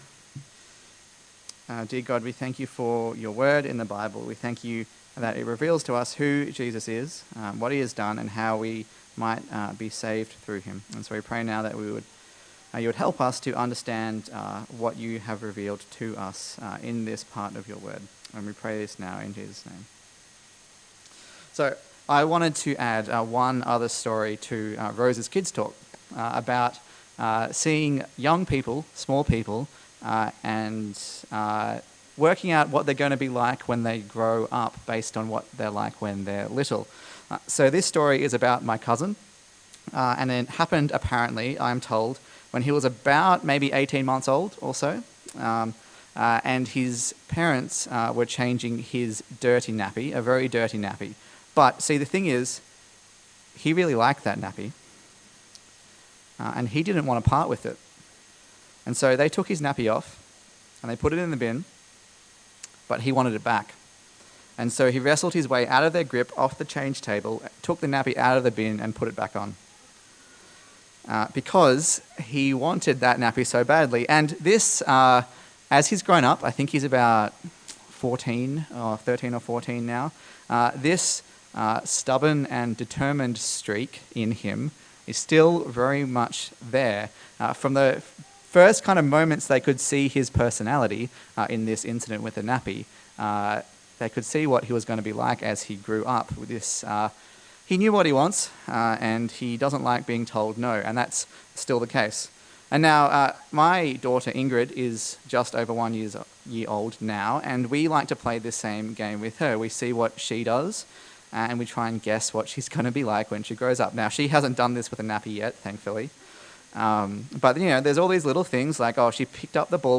Luke Passage: Luke 2:22-52 Service Type: Sunday Morning